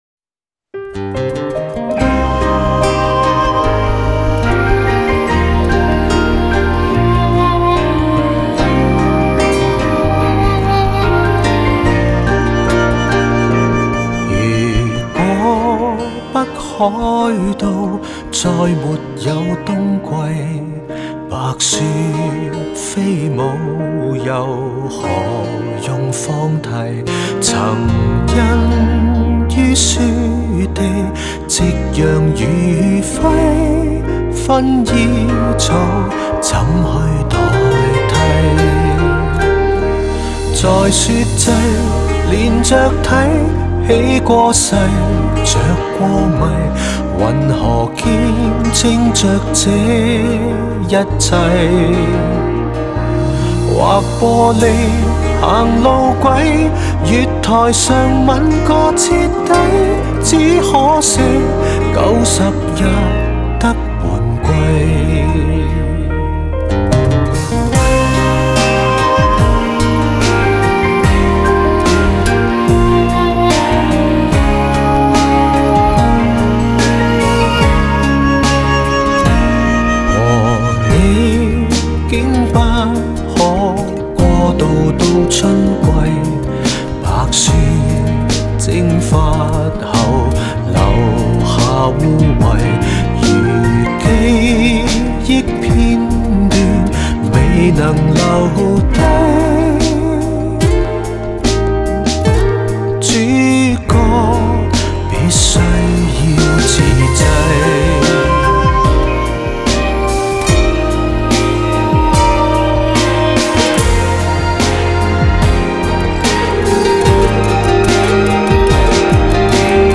在线试听为压缩音质节选